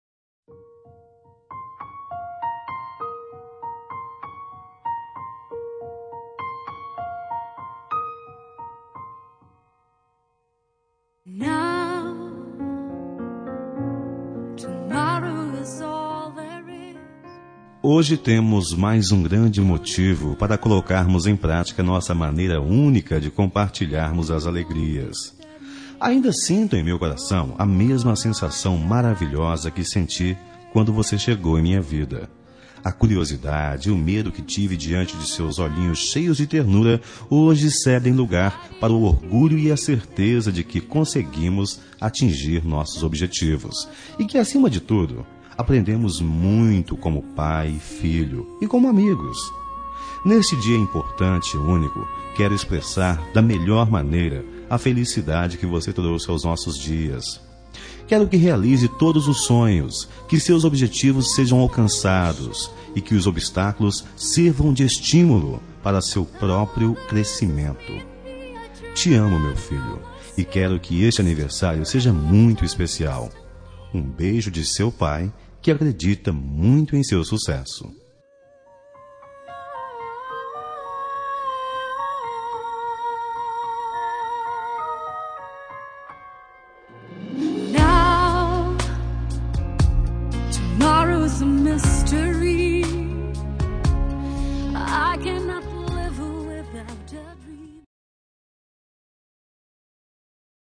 Telemensagem de Aniversário de Filho – Voz Masculina – Cód: 1863